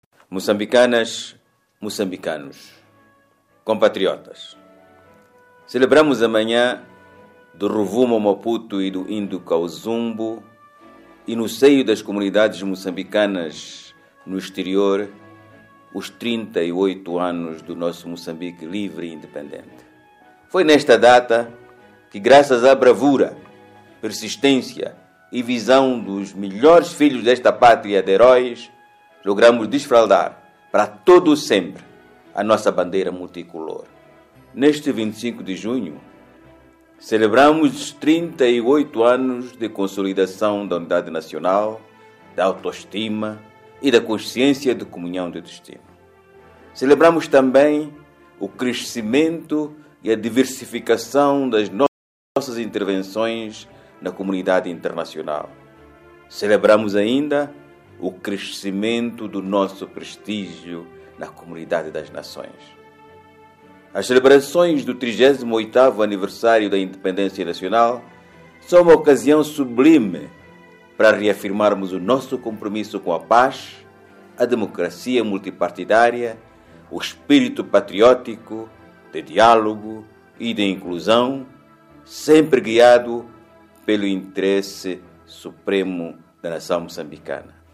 Extrato do discurso do presidente Guebuza - 01:27